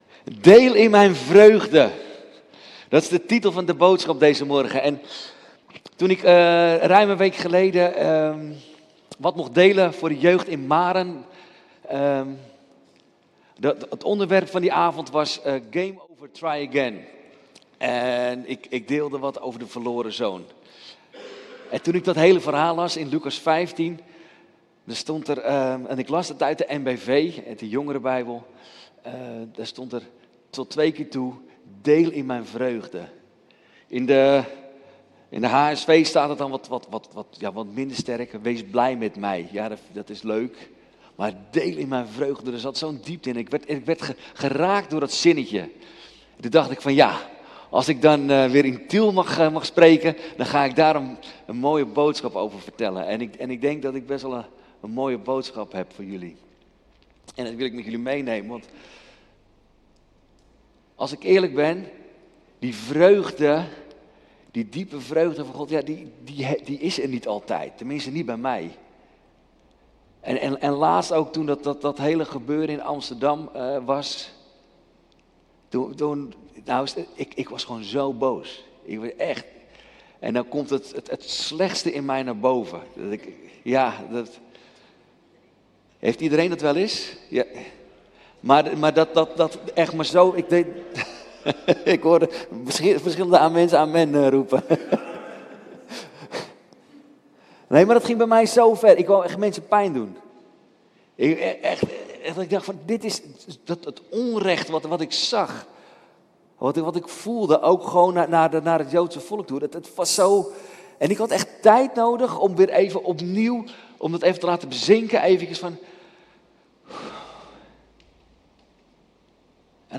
De preek legt de focus op: Vreugde als gave van God : Een vreugde die dieper gaat dan tijdelijke blijdschap en die in alle situaties kracht, genezing en perspectief biedt.